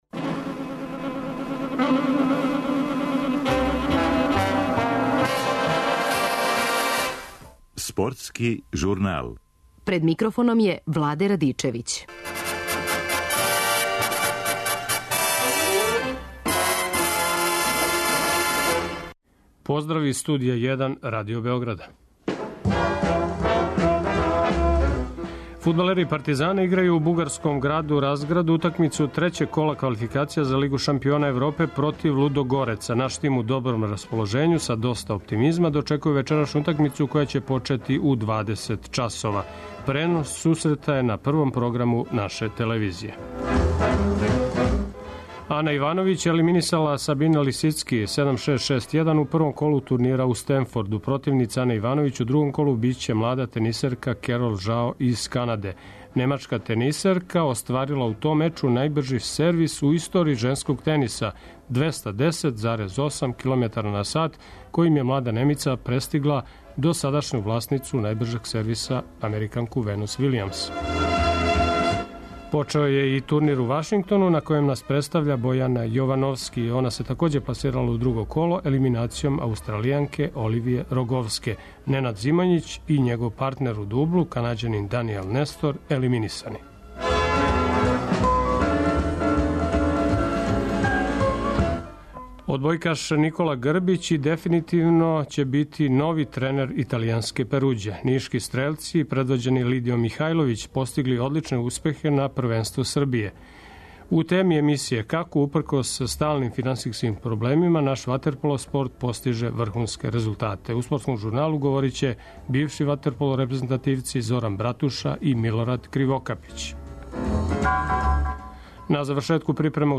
преузми : 13.42 MB Спортски журнал Autor: Спортска редакција Радио Београда 1 Слушајте данас оно о чему ћете читати у сутрашњим новинама!